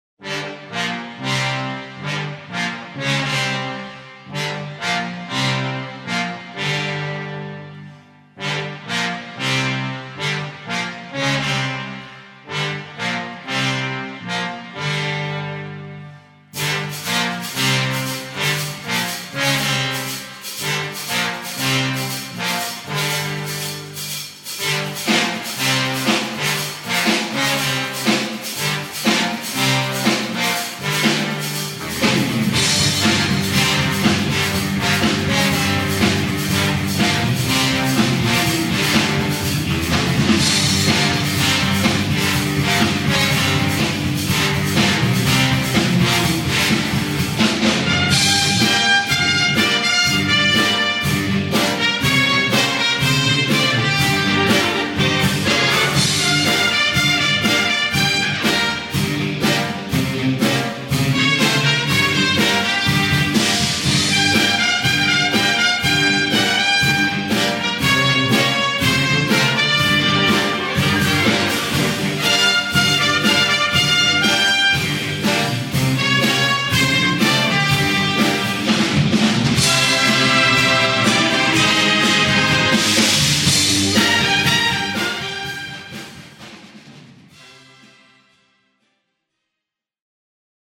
Recueil pour Harmonie/fanfare